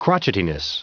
Prononciation du mot crotchetiness en anglais (fichier audio)
Prononciation du mot : crotchetiness